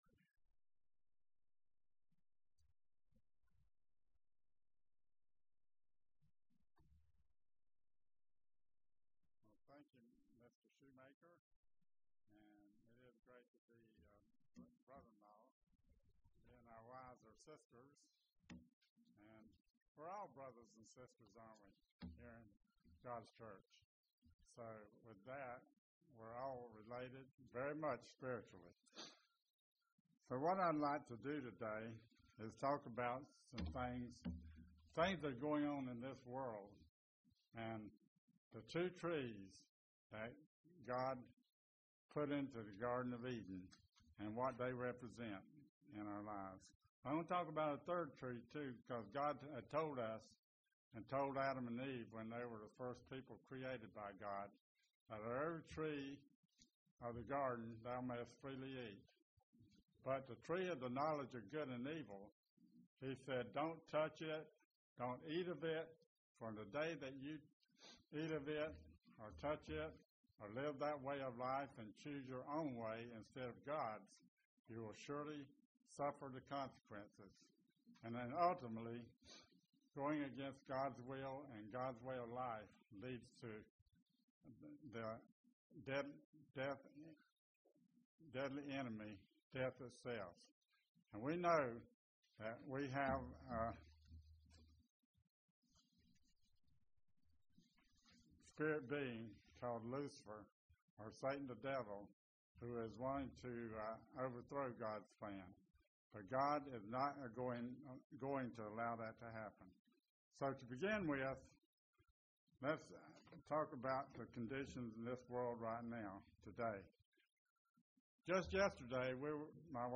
This sermon brings up the question of why does man alwasy sin? Generation after generation commit the same sins over and over.
Given in Paintsville, KY